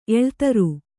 ♪ eḷtaru